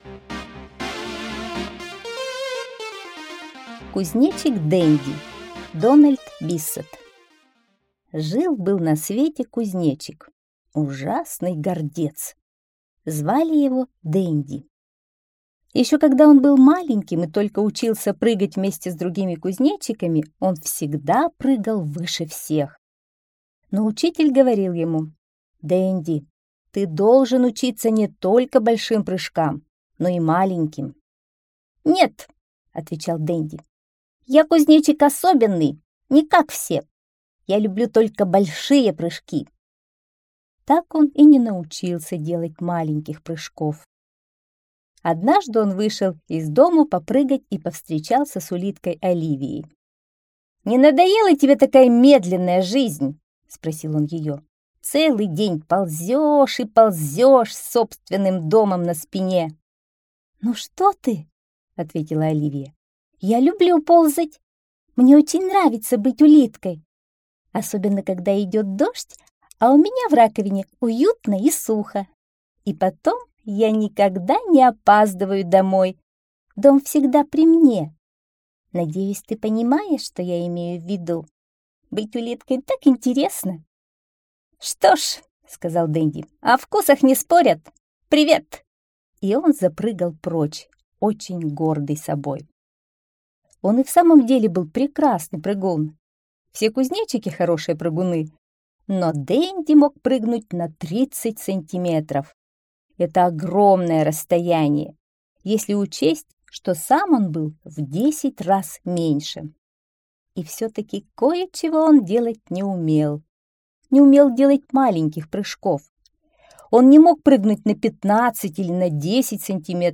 Кузнечик Денди - аудиосказка Дональда Биссета.